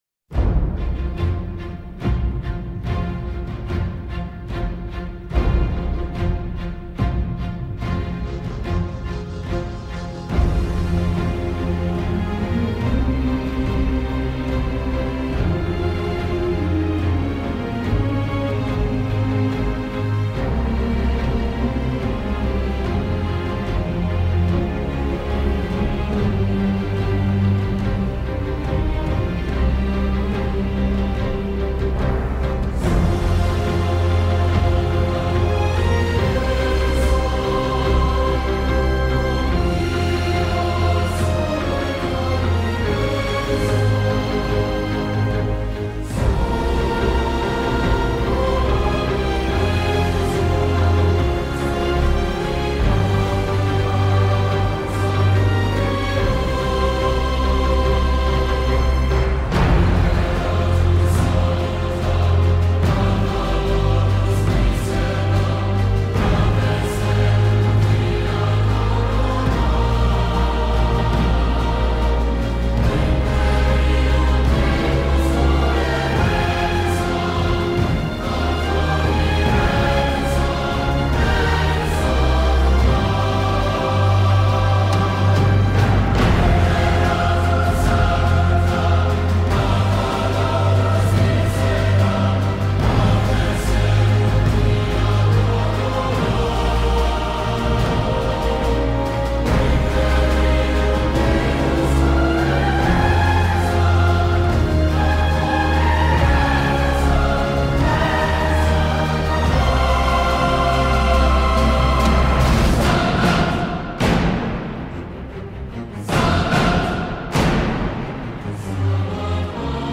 Angelus e corteo folcroristico nella giornata dell’epifania. con PAPA FRANCESCO Angelus e corteo folcroristico nella giornata dell’epifania. con PAPA FRANCESCO